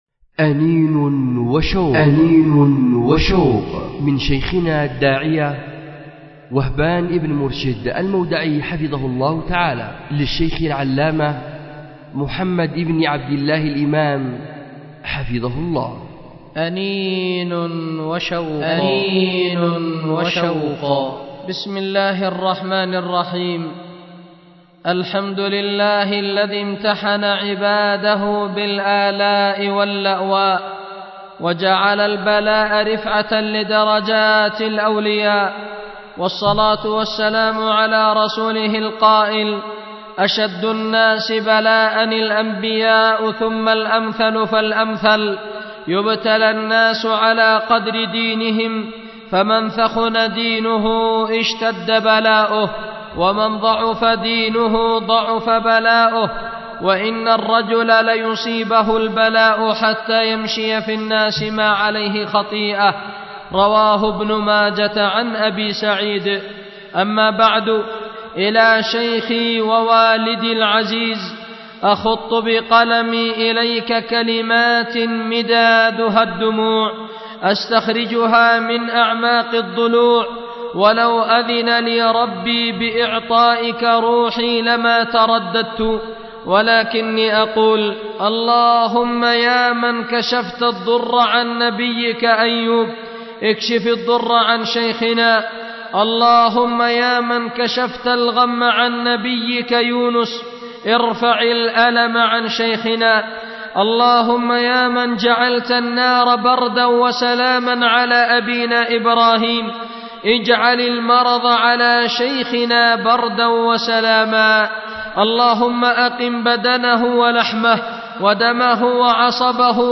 أُلقيت بدار الحديث للعلوم الشرعية بمسجد ذي النورين ـ اليمن ـ ذمار